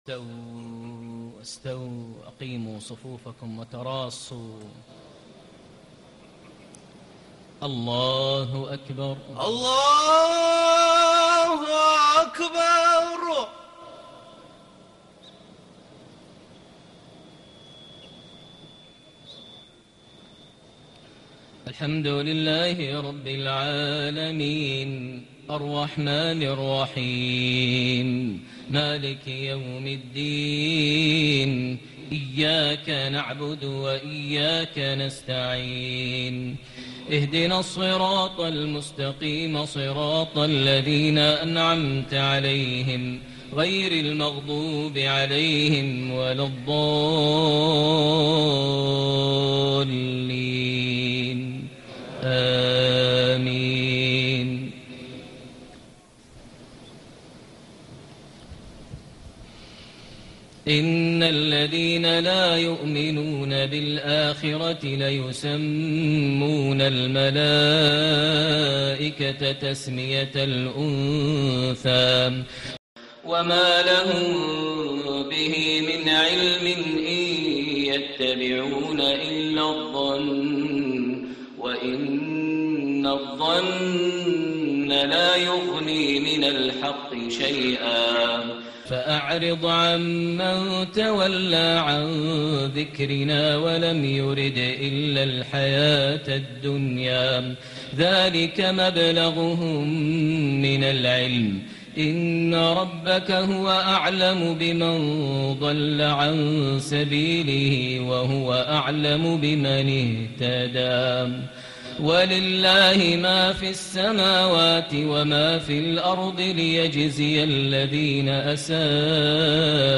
صلاة العشاء٣٠ ذو القعدة ١٤٣٨هـ سورة النجم ٢٨-٤٧ > 1438 هـ > الفروض - تلاوات ماهر المعيقلي